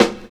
99 SNARE.wav